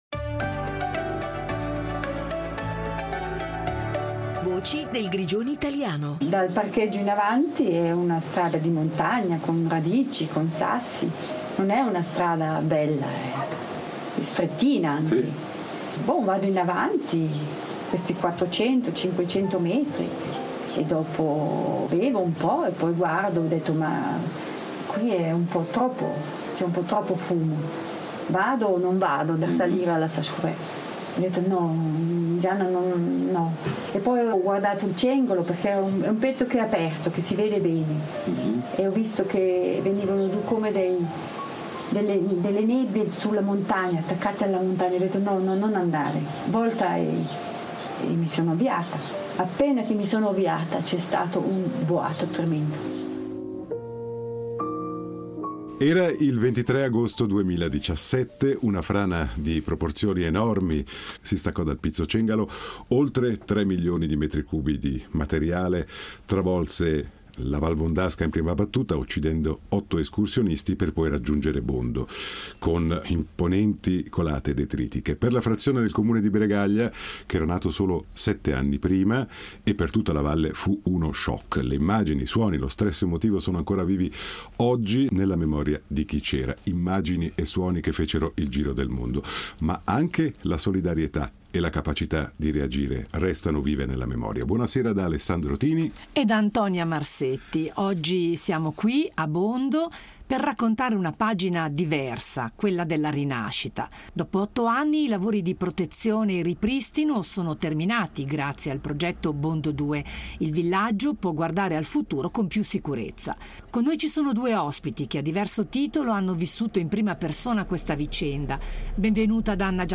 Ospiti della trasmissione: Anna Giacometti, consigliera nazionale ed ex sindaca, che visse in prima persona i giorni dell’emergenza, e Fernando Giovanoli, attuale sindaco, che ha seguito la lunga fase di ricostruzione.